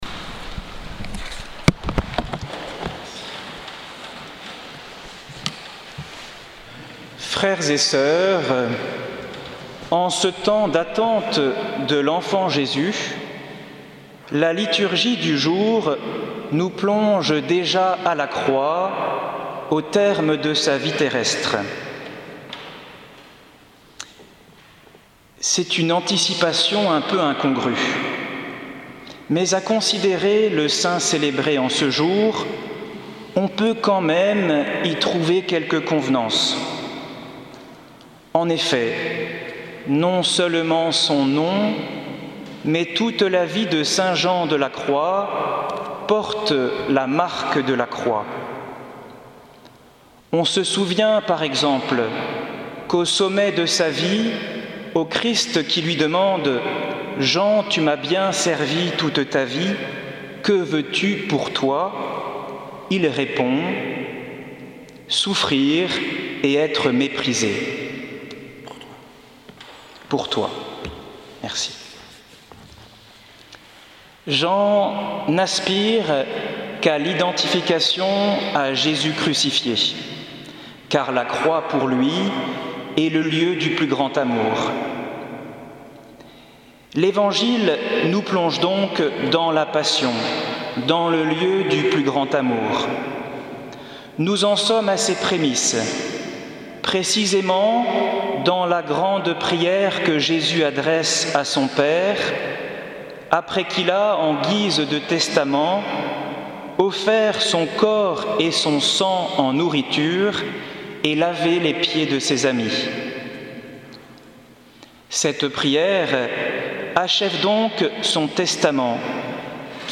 Homélie pour la saint Jean de la Croix 14/12/17 | Les Amis du Broussey